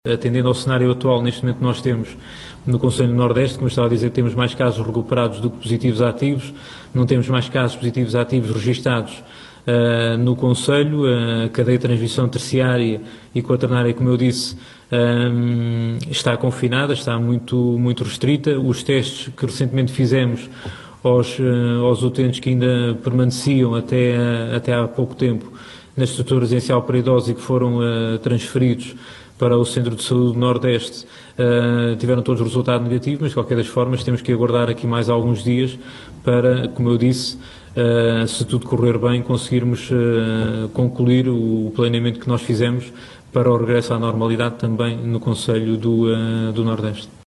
O responsável pela Autoridade de Saúde Regional admitiu, esta segunda-feira, no habitual ponto de situação sobre o surto no arquipélago, que a cerca sanitária do Nordeste vai perdurar “por mais alguns dias”.